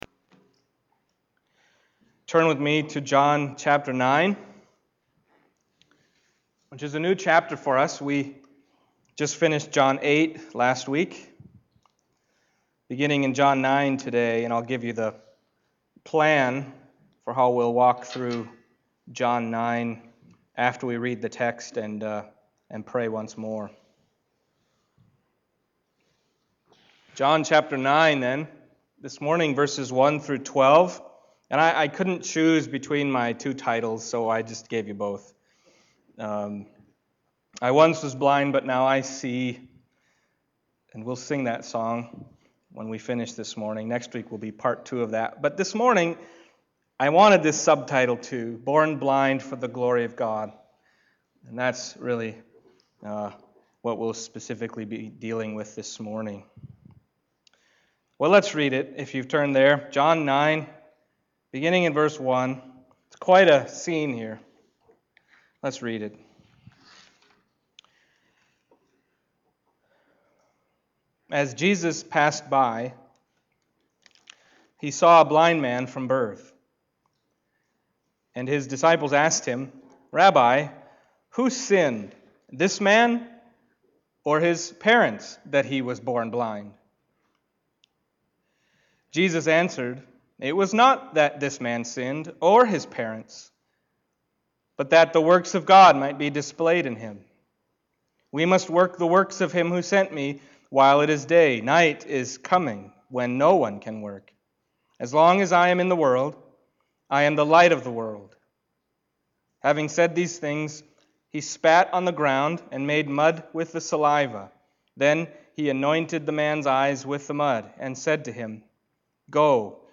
John Passage: John 9:1-12 Service Type: Sunday Morning John 9:1-12 « True Freedom Forever I Once Was Blind